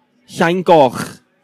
Summary Description Llaingoch.ogg Cymraeg: Ynganiad o enw'r pentref yn y dafodiaith leol.